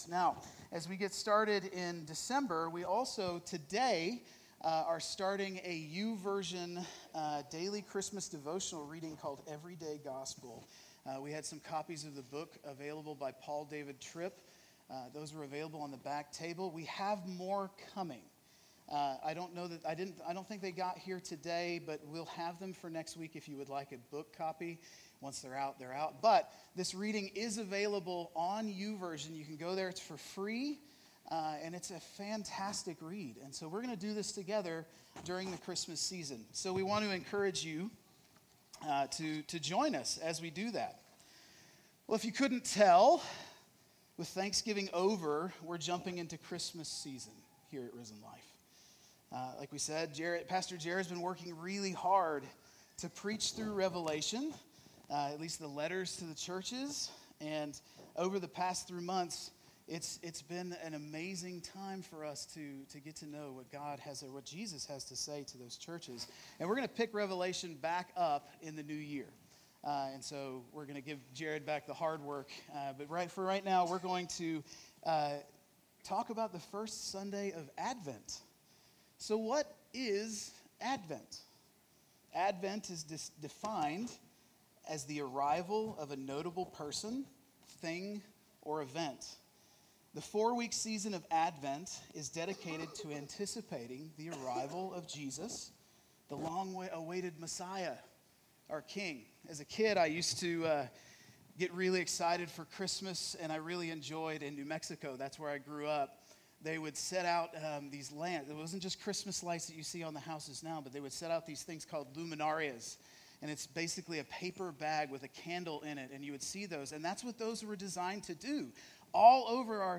Worship Listen Sermon This Sunday we will be pushing pause on our series in Revelation and focus on the arrival of Jesus with a new series “Whispers of His Coming”.